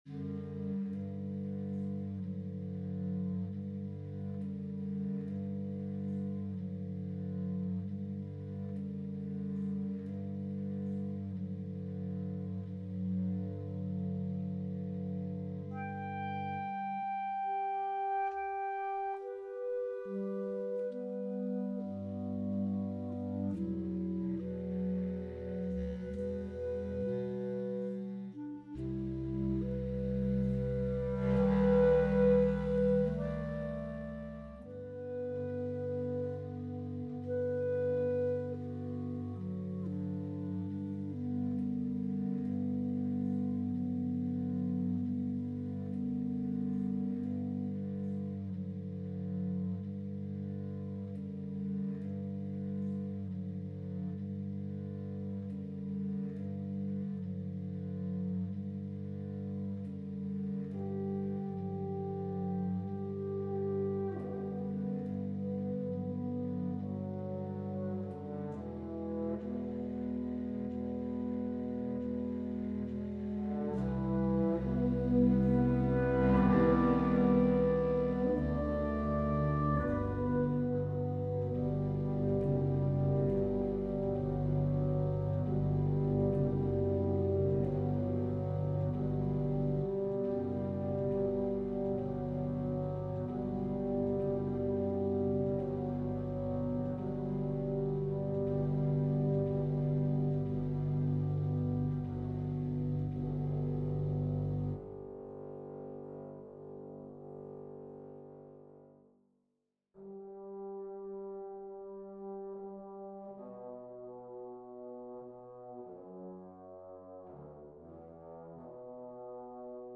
This is a piece I wrote in mid 2024 for concert band.
brass concerto long longcatislong microtonal orchestral trombone
The second movement 'chronic—not curable' is about relentless sickness. The quartertonal harmony in this movement constantly planes around as if dazed, nauseous and weak. Huge chords crash in like streams of light too bright for our eyes— yet unasked; unrelentingly; the call is still there.